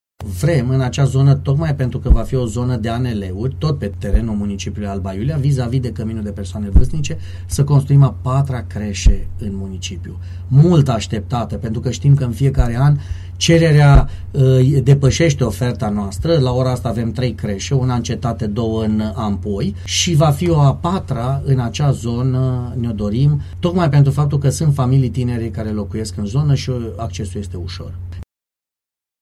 Despre această creșă a vorbit la Unirea FM primarul Gabriel Pleșa.